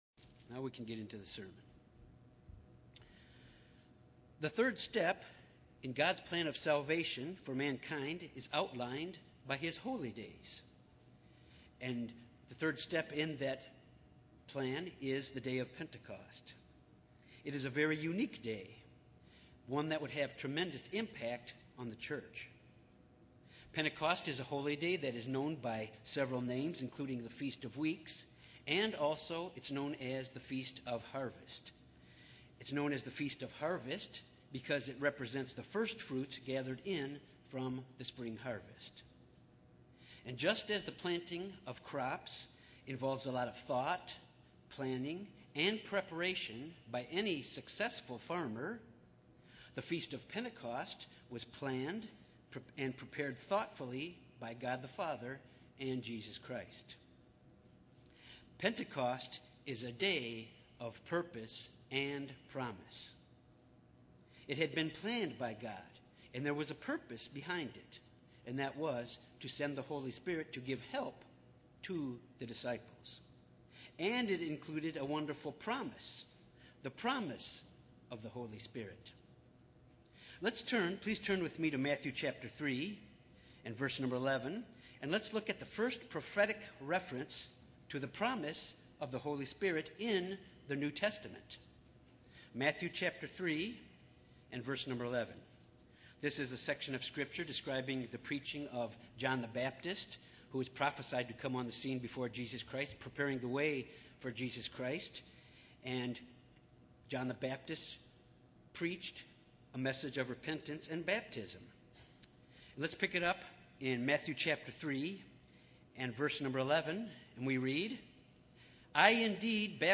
Split-sermon.